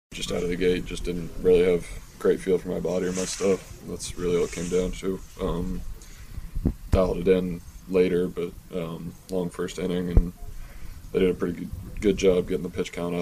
Paul Skenes says he didn’t have it last night.